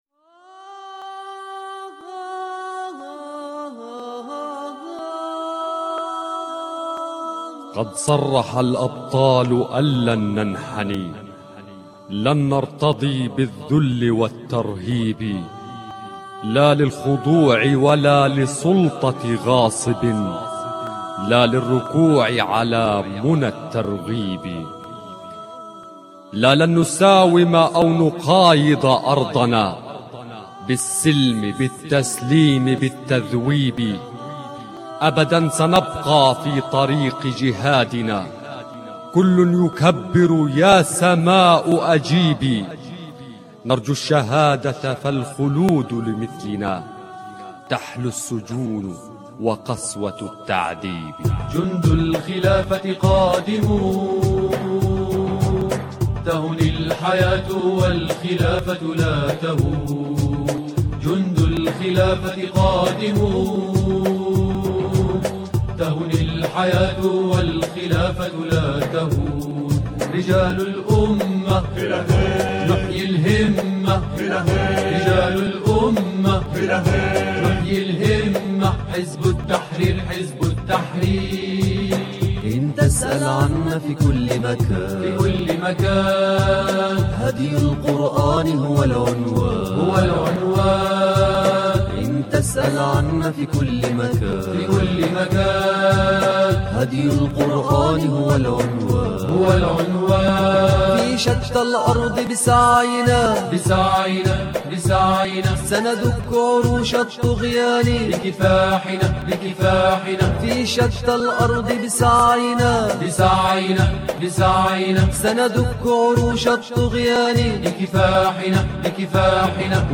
أنشودة